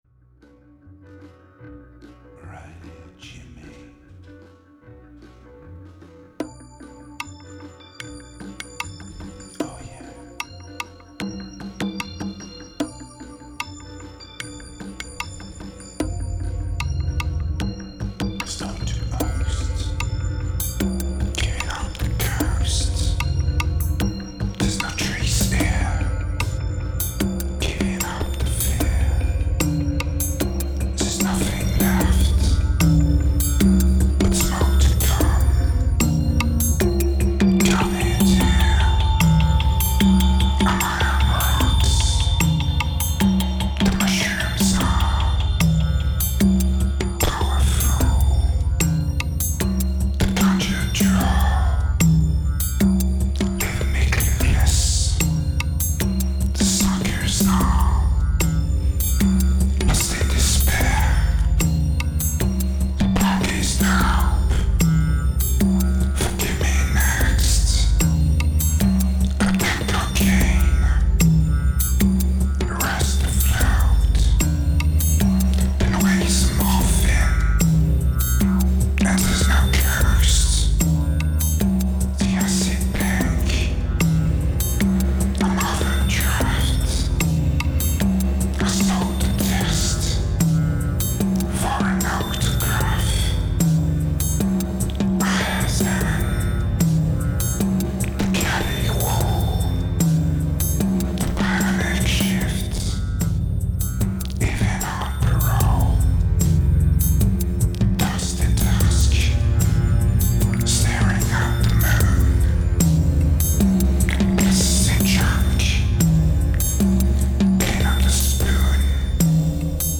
2330📈 - -68%🤔 - 75BPM🔊 - 2009-03-14📅 - -498🌟